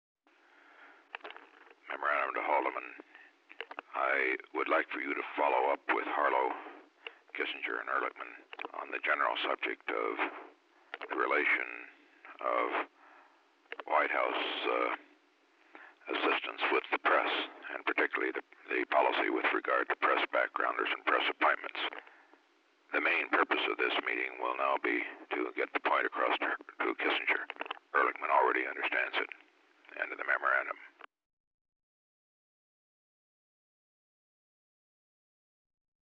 Segment 6 Subject:  Dictation of a memo by the President to Haldeman about the White House assistants’ relations with the press